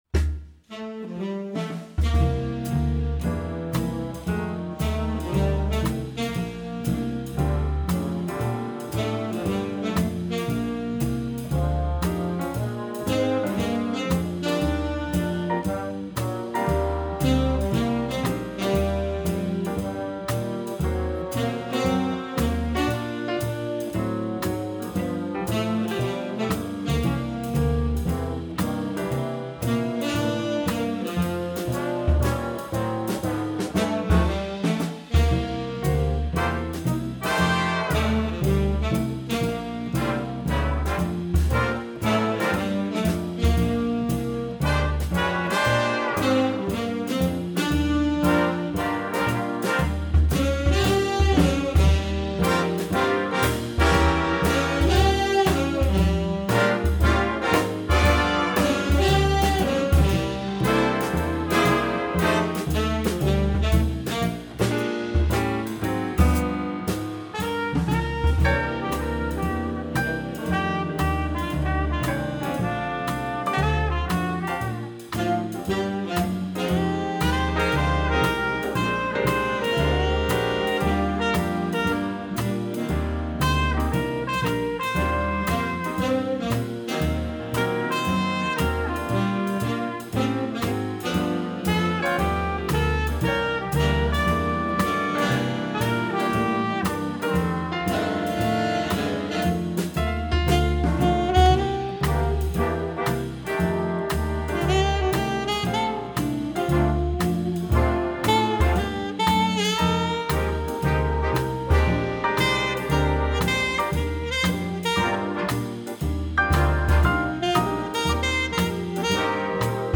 Répertoire pour Jazz band